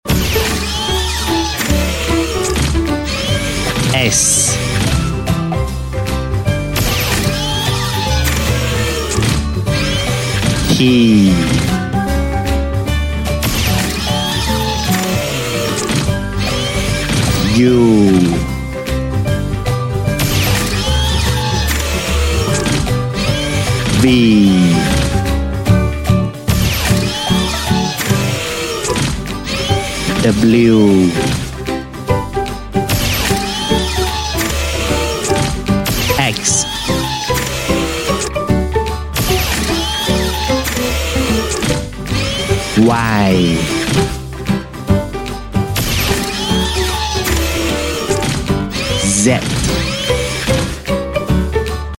Robot Alphabet (S Z) sound effects free download